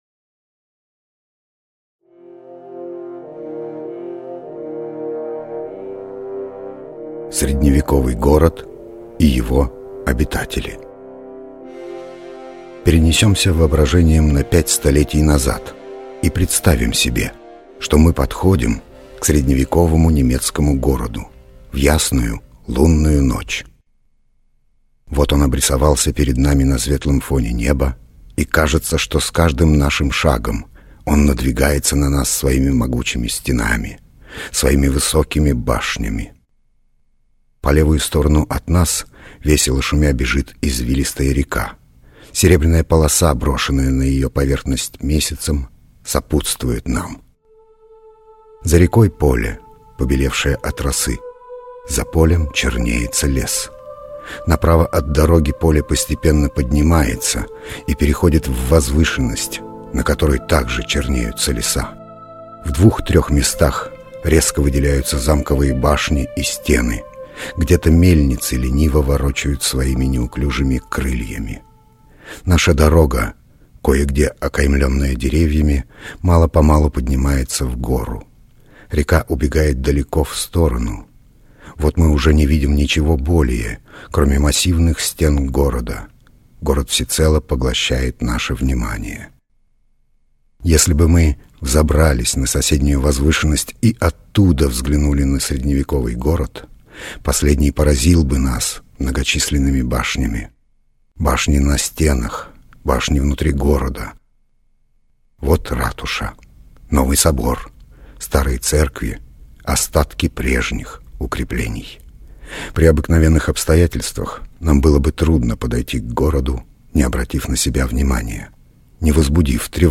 Аудиокнига Жизнь средневекового города | Библиотека аудиокниг
Aудиокнига Жизнь средневекового города Автор Константин Иванов Читает аудиокнигу Павел Смеян.